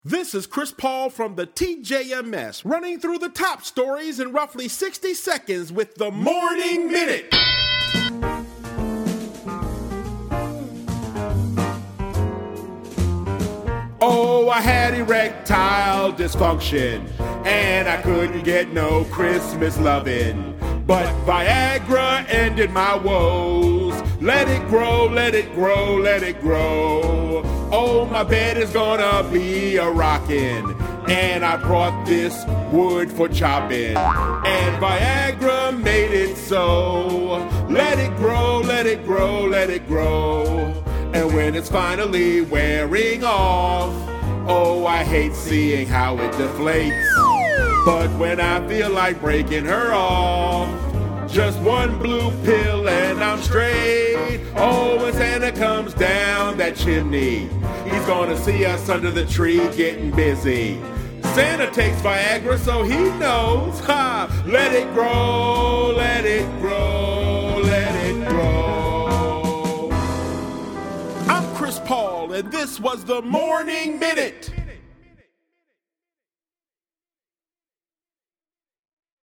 Click the link above to laugh out loud.